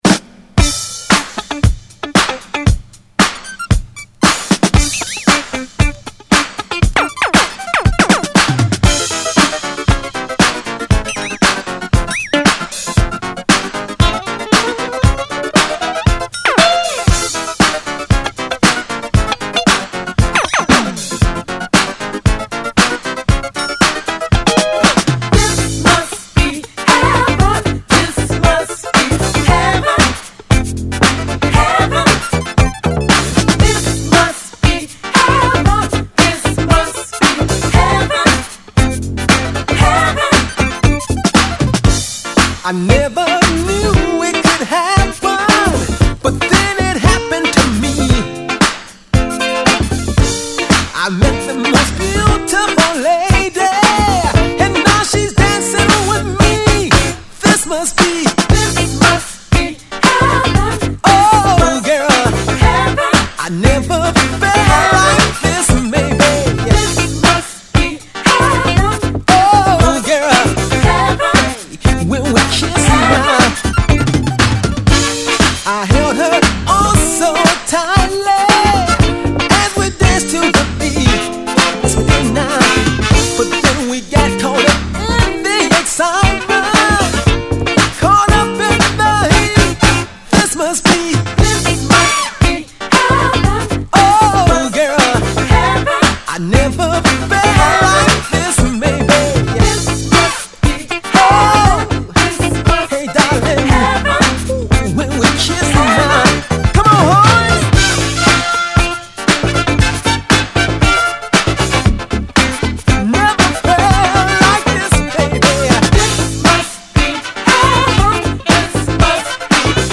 中盤のBreakも◎!!